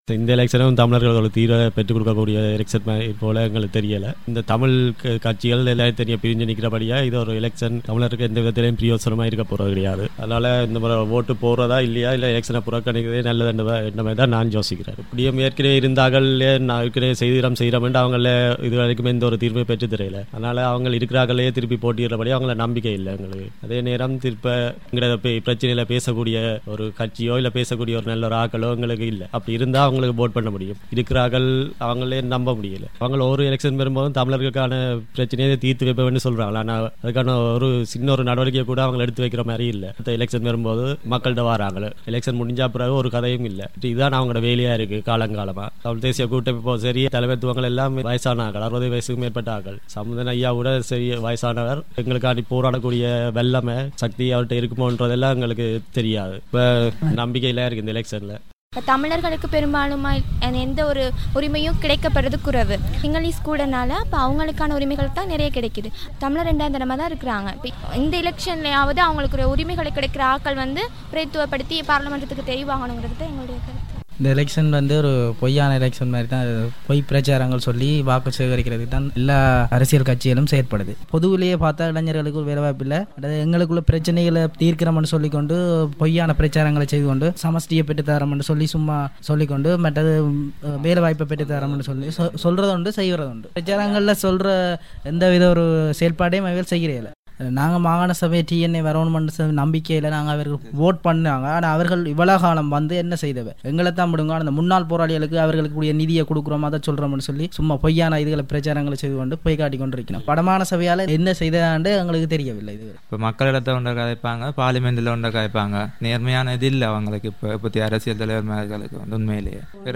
ஆகிய எல்லாவிதமான கேள்விகளுக்குமான பதில்களை அவர்களின் சொந்தக் குரல்களிலேயே இங்கே கேட்கலாம்.